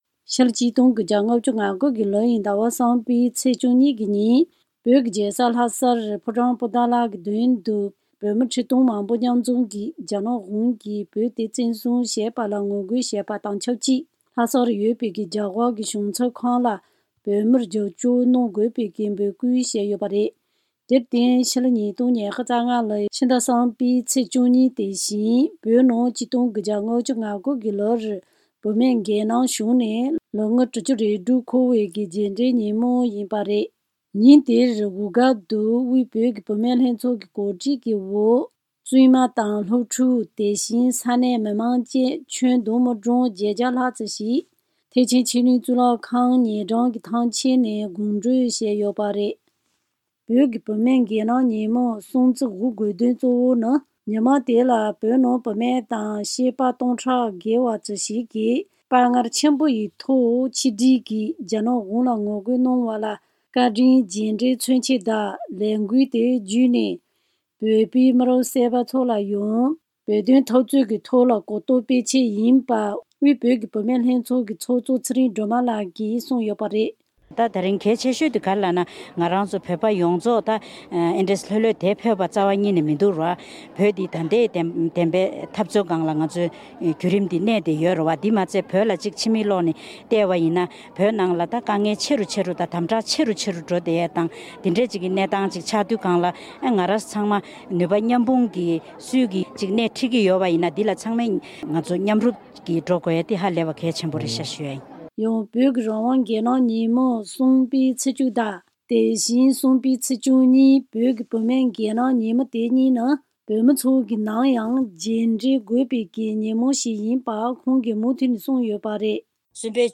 བཅའ་འདྲི་དང་གནས་ཚུལ་ཕྱོགས་བསྡུས